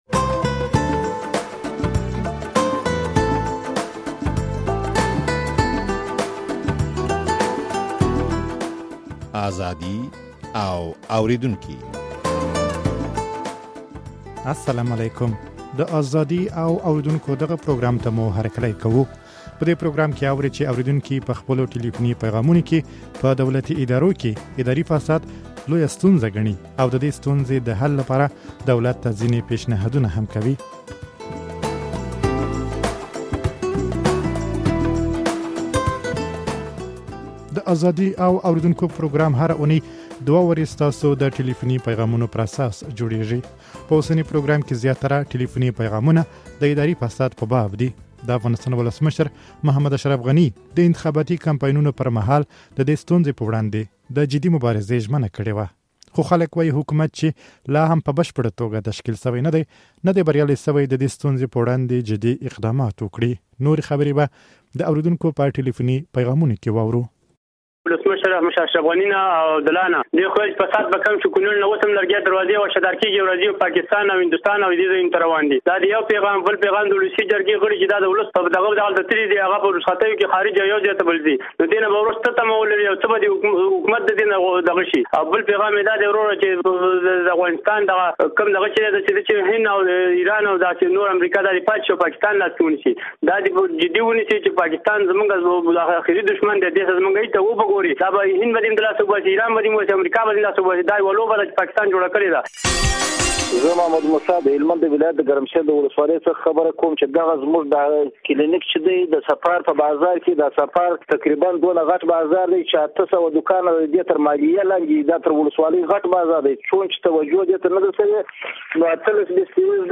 په دې پروګرام کې اورئ چې اورېدونکي په خپلو ټليفوني پيغامونو کې په دولتي ادارو کې اداري فساد د افغانستان لويه ستونزه ګڼي